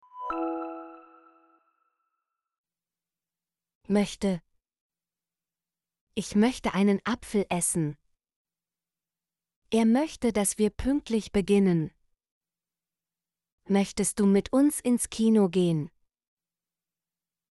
möchte - Example Sentences & Pronunciation, German Frequency List